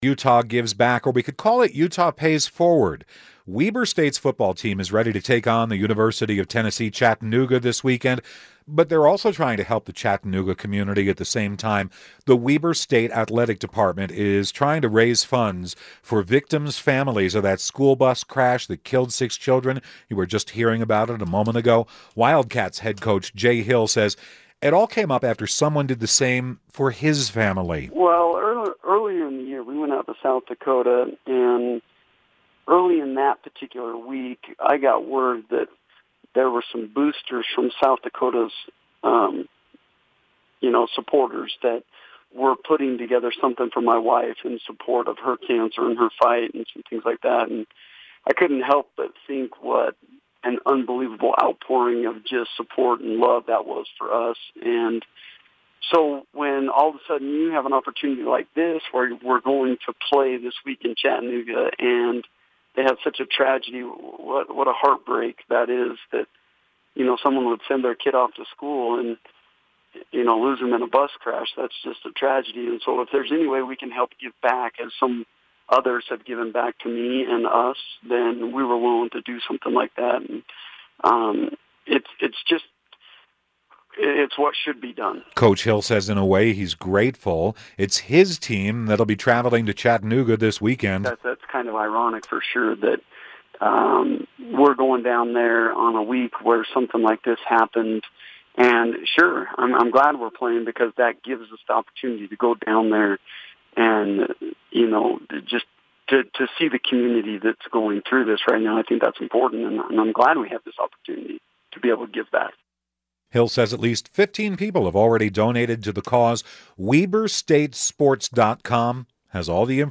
spoke with KSL Newsradio about the effort.